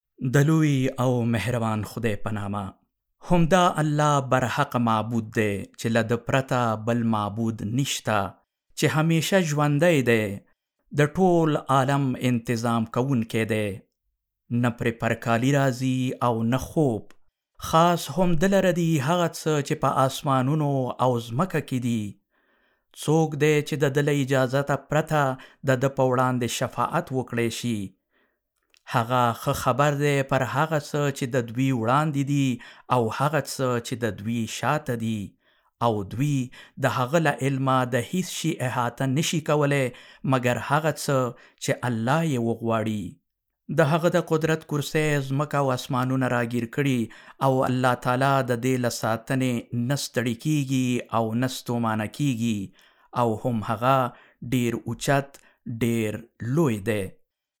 Male
Adult
Holy-Quran